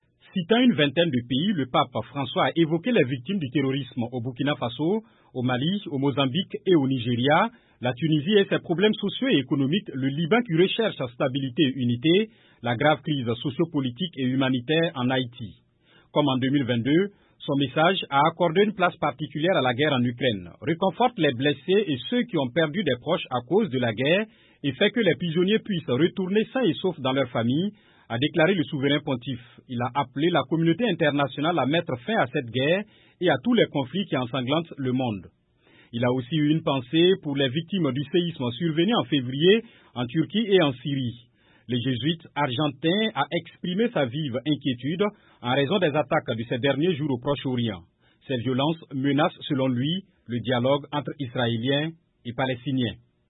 Le pape François a condamné dimanche les nombreuses "pierres d'achoppement" à la paix dans le monde lors de son traditionnel message "Urbi et Orbi" pour la fête de Pâques. C’était devant 100.000 fidèles massés sur la place Saint-Pierre au Vatican.